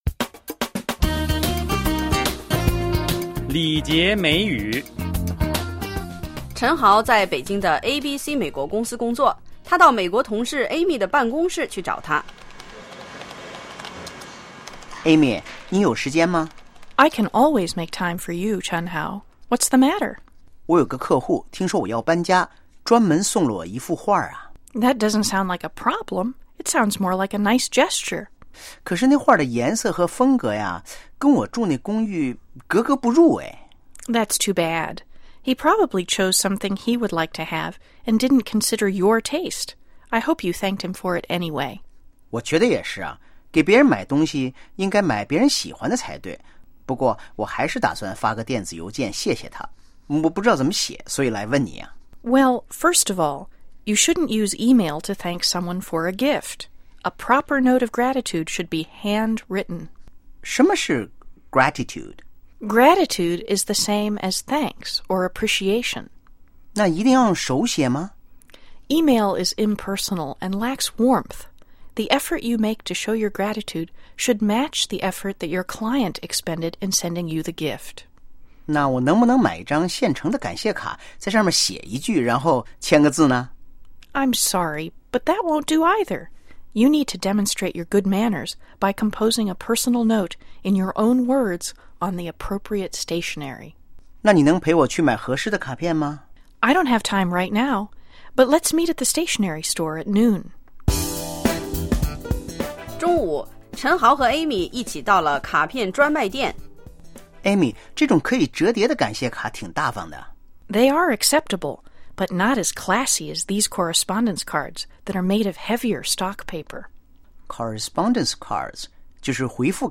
(Office ambience)